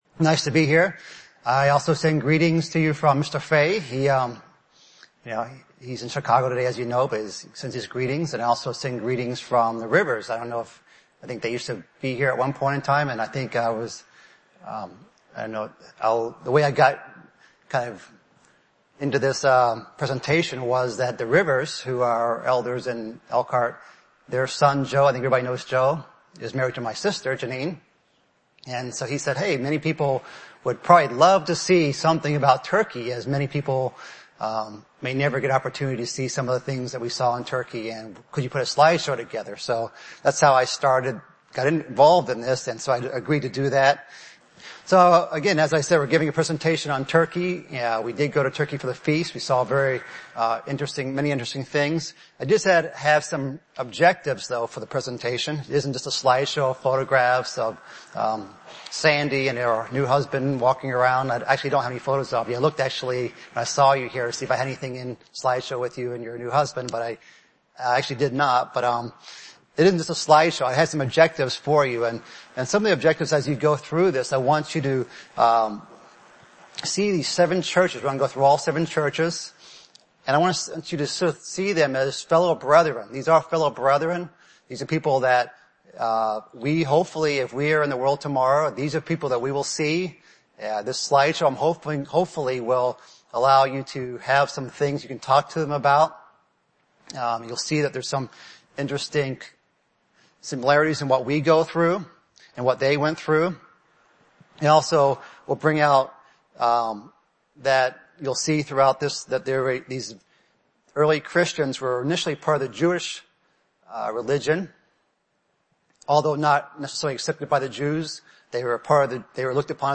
Bible Study - Revelation Series